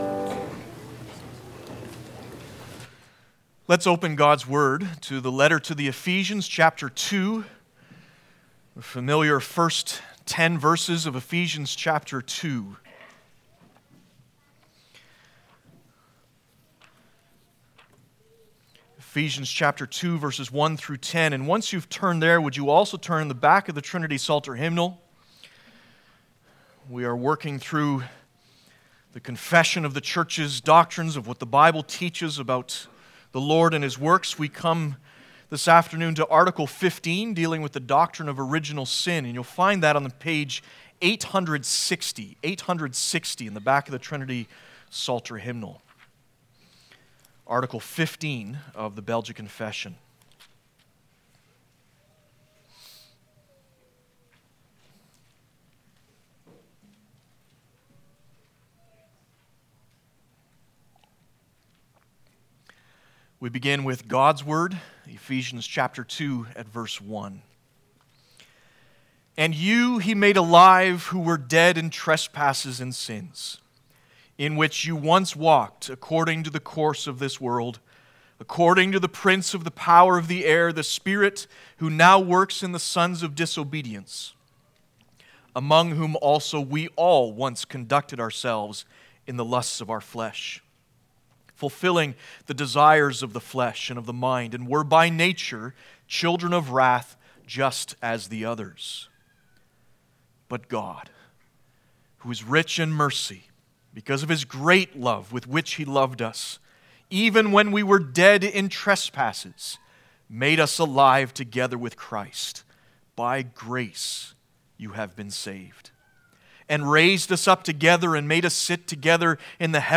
Belgic Confession Passage: Ephesians 2:1-10 Service Type: Sunday Afternoon « Who’s in Charge?